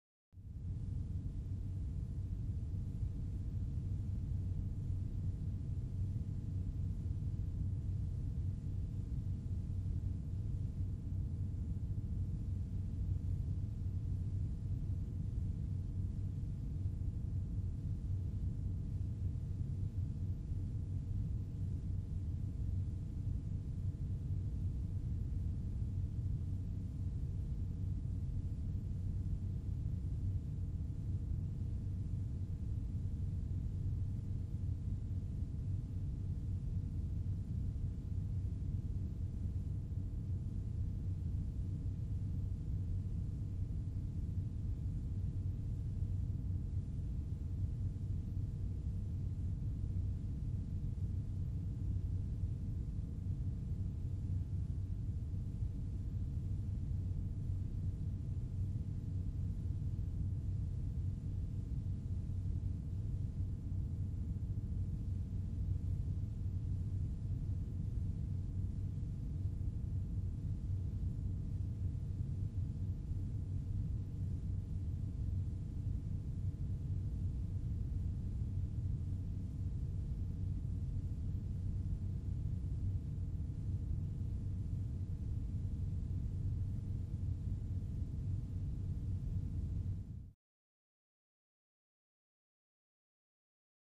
Room Tone - Good Tone